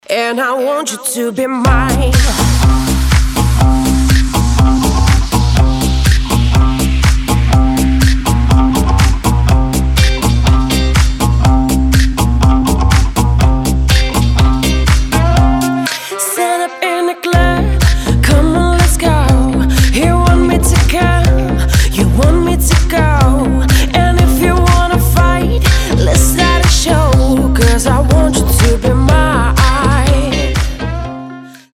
Категория: Танцевальные рингтоны